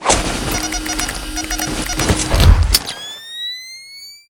battlesuit_wear.ogg